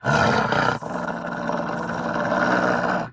Divergent/bdog_growl_2.ogg at 255081e1eea8a9d8766ef0be22fed2081c66c9e2
bdog_growl_2.ogg